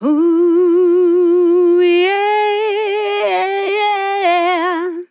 Categories: Vocals Tags: DISCO VIBES, dry, english, female, fill, OH, sample, YEAH
POLI-Vocal-Fills-120bpm-Fm-1.wav